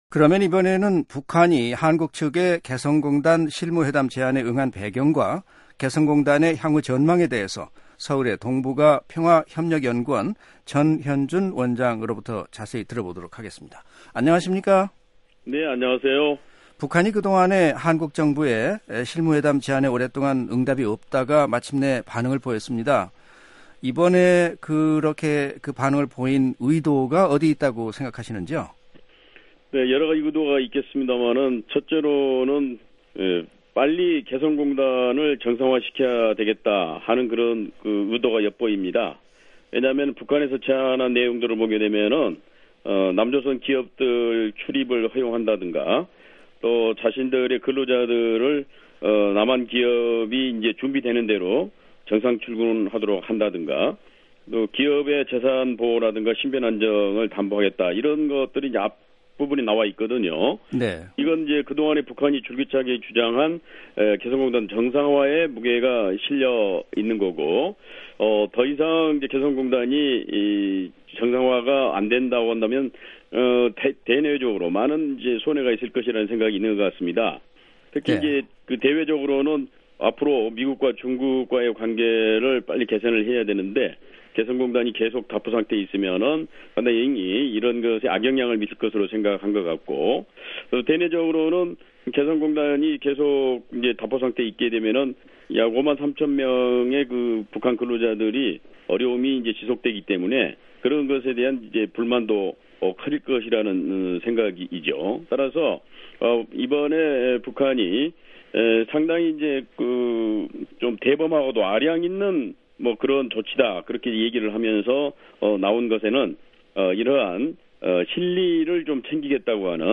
인터뷰